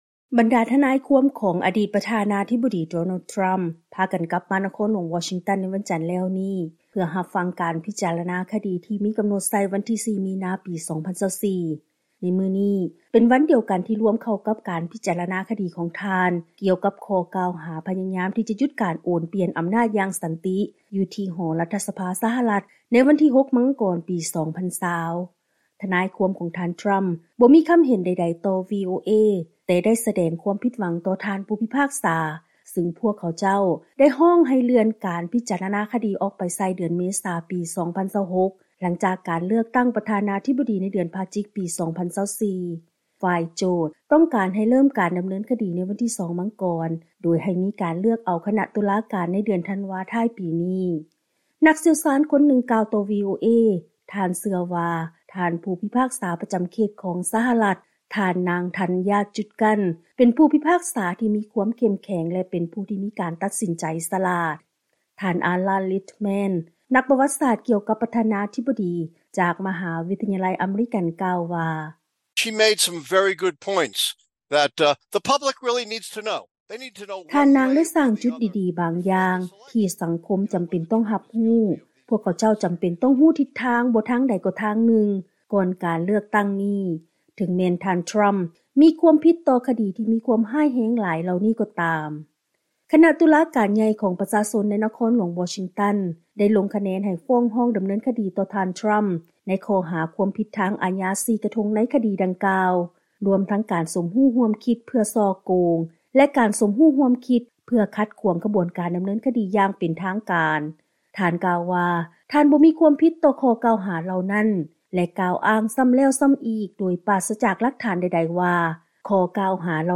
ຟັງລາຍງານກ່ຽວກັບ ການປະເຊີນໜ້າກັບການດໍາເນີນຄະດີ ທ່າມກາງການໂຄສະນາຫາສຽງ ເພື່ອການເລືອກຕັ້ງປະທານາທິບໍດີຂອງ ທ່ານທຣໍາ